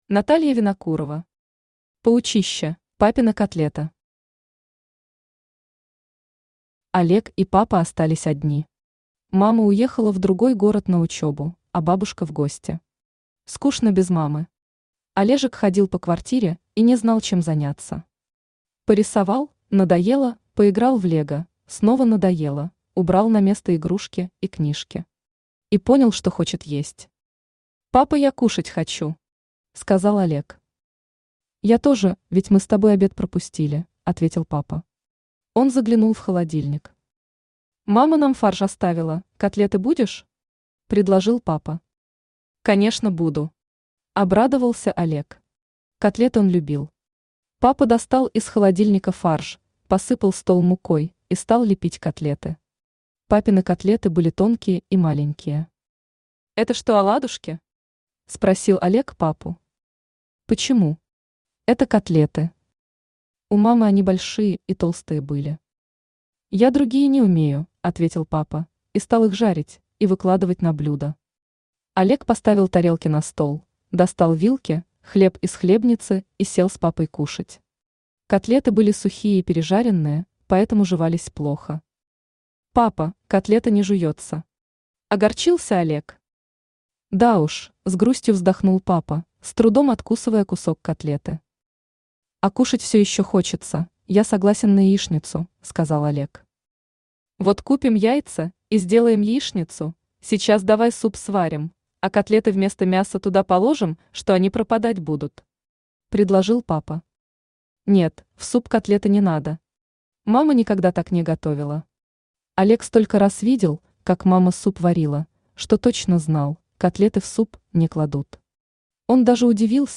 Аудиокнига Паучище | Библиотека аудиокниг
Aудиокнига Паучище Автор Наталья Николаевна Винокурова Читает аудиокнигу Авточтец ЛитРес.